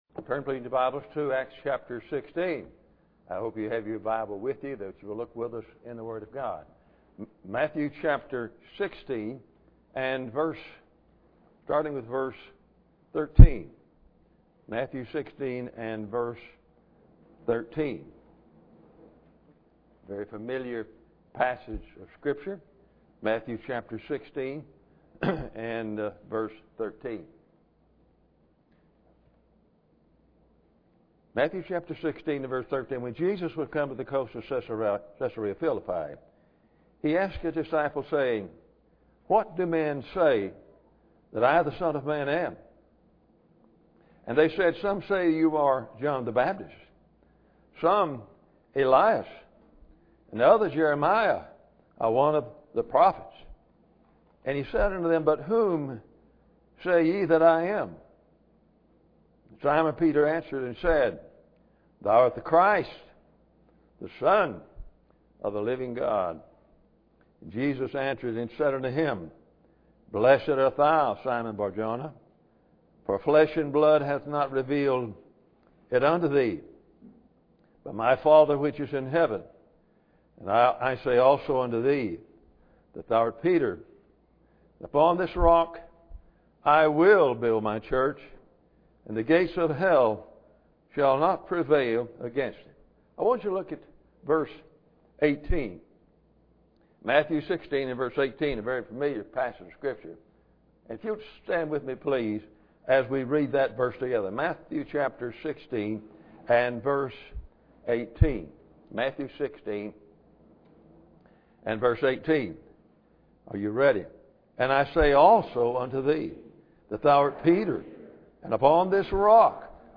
Matthew 16:13-18 Service Type: Sunday Evening Bible Text